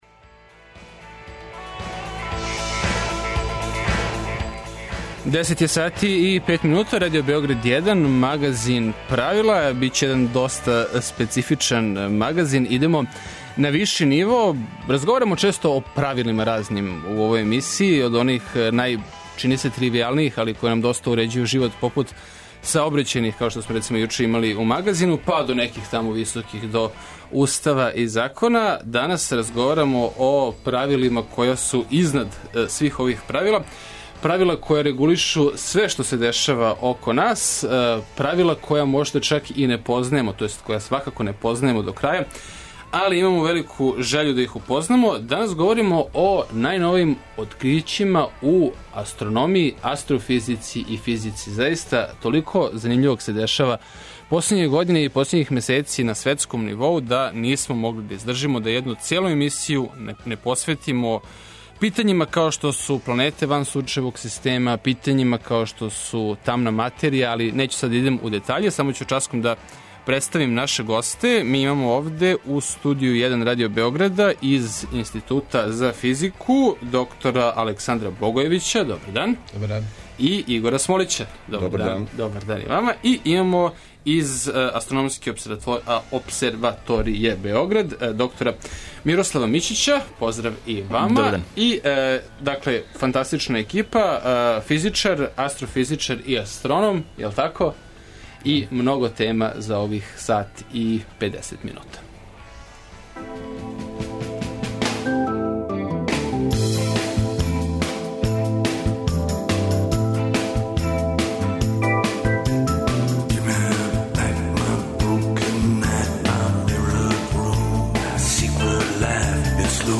Потврђено је постојање тамне материје и откривена планета на којој готово сигурно постоји живот, и то на далеко мањој удаљености од Земље него што је то ико очекивао. О значају ових открића разговарамо са нашим врхунским стручњацима.